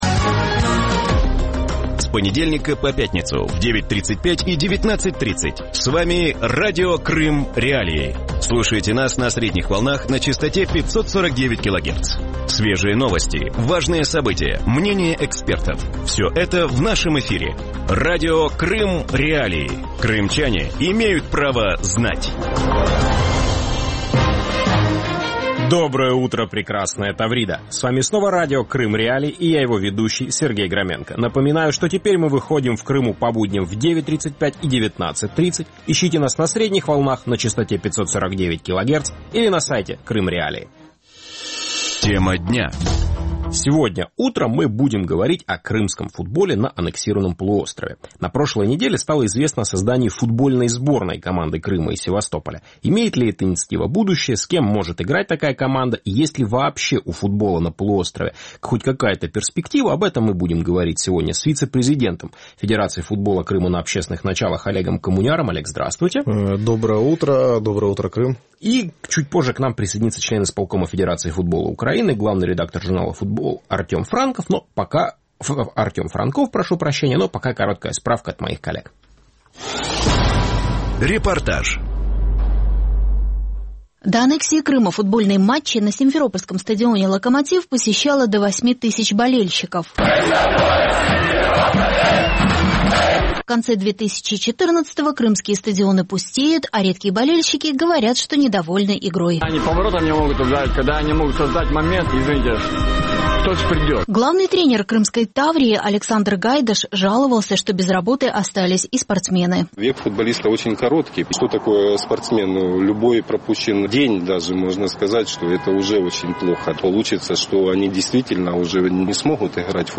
Утром в эфире Радио Крым.Реалии говорят о крымском футболе.